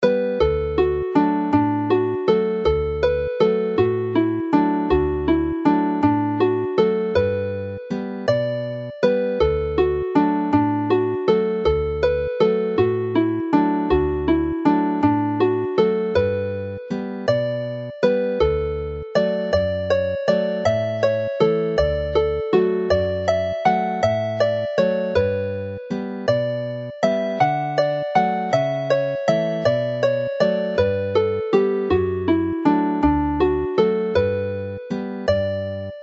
Play the tune slowly